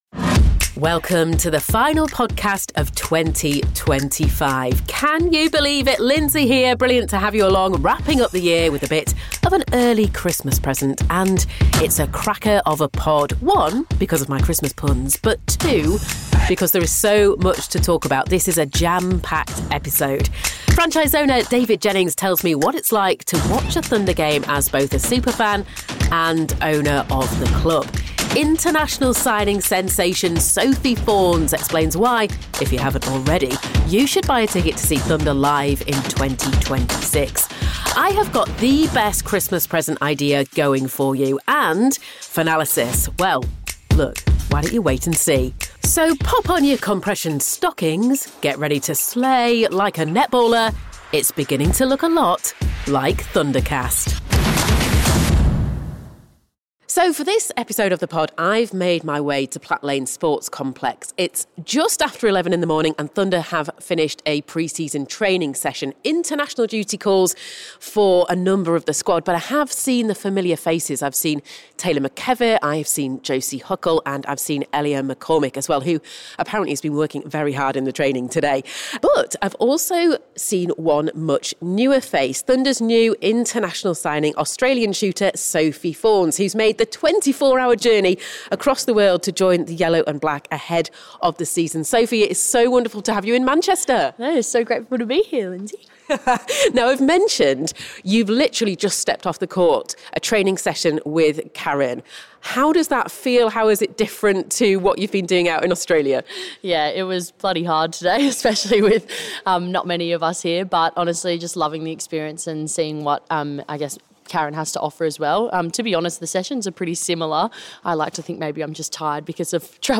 Thundercast Netball Podcast / It’s beginning to look a lot like Thundercast…